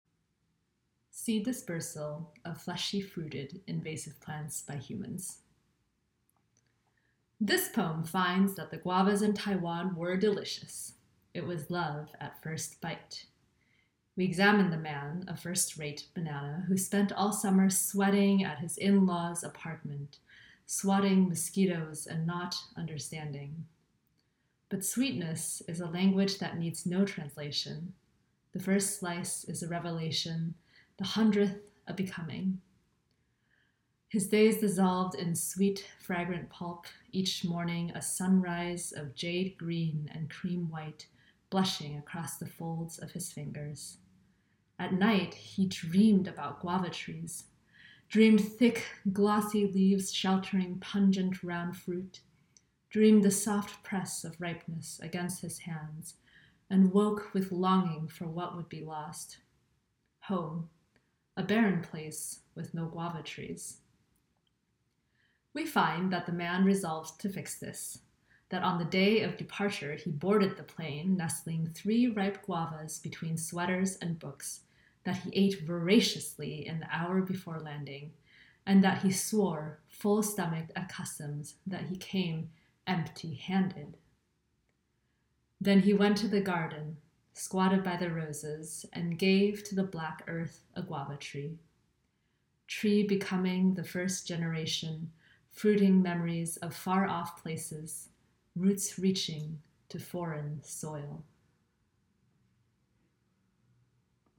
Or you can let them read to you!